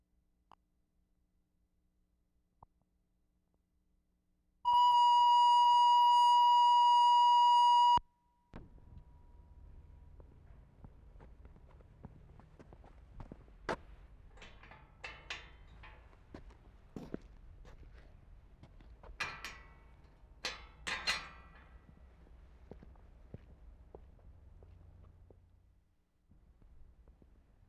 WORLD SOUNDSCAPE PROJECT TAPE LIBRARY
OPENING VARIOUS GATES in Dollar.